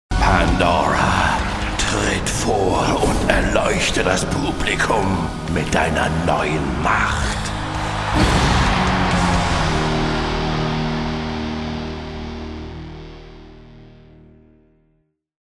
Narrator: